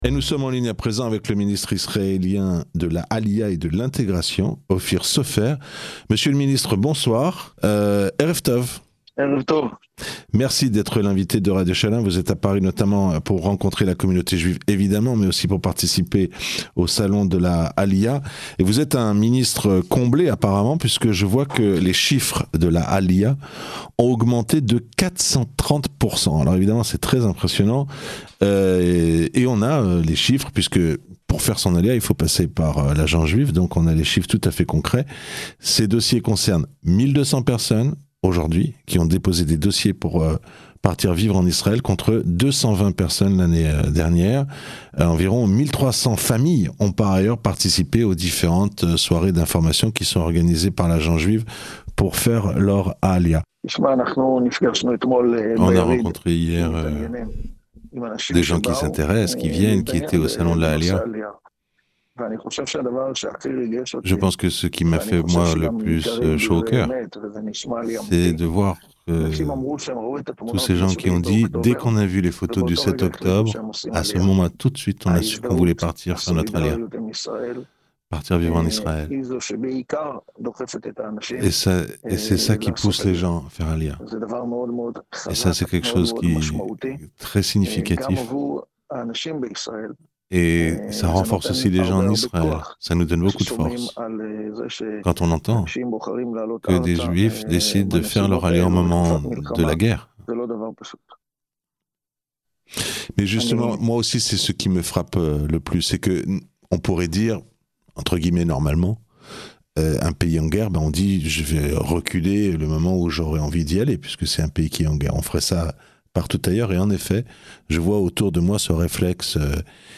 Rencontre avec le ministre israélien de la alyah : l'alyah de France a fait un bond de 430% depuis le 7 octobre